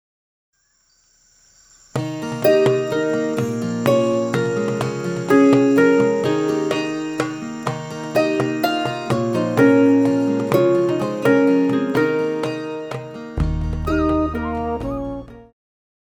Pop
French Horn
Band
Instrumental
World Music,Electronic Music
Only backing